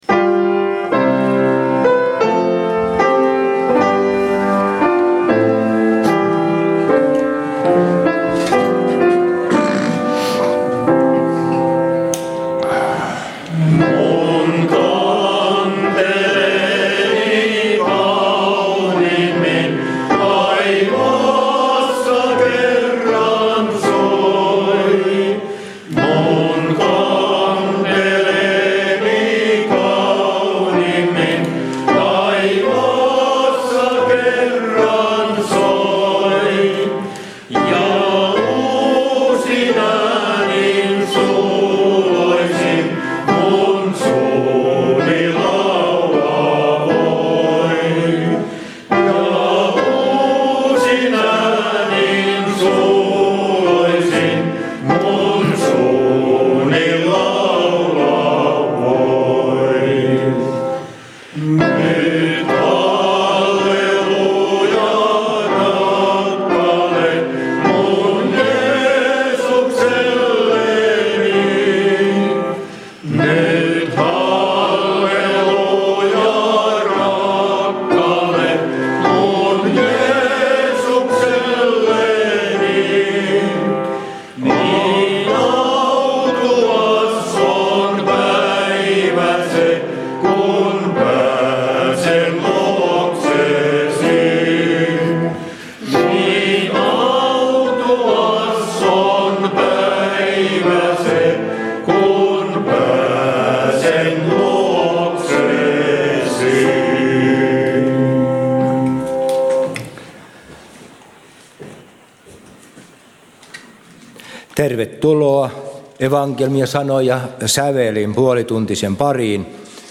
Teuvan Veteraaniperinne-kuoro Siionin Kanteleen laulut
342 Teuvan srk-sali